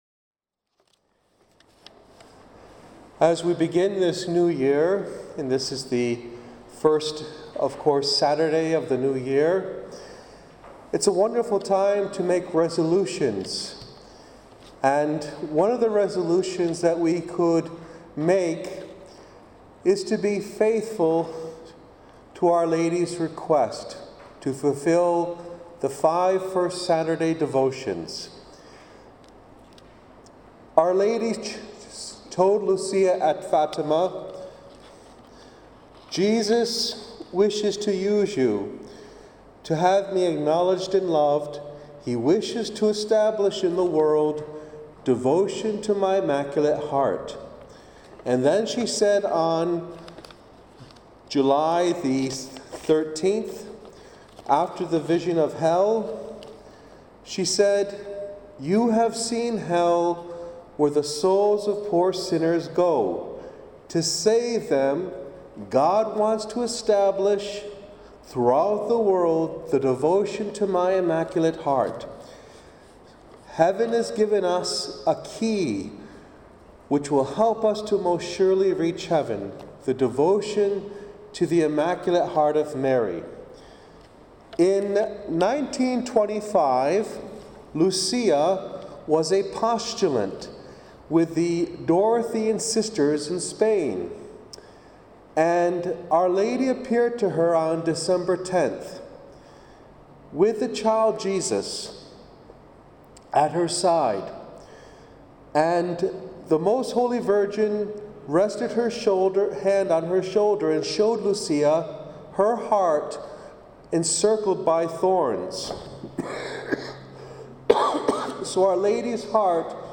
speaks on Our Lady's request for the 5 First Saturdays during the "Day With Mary" held at St. Pius X Parish in Manning, Western Australia on 5 January 2013.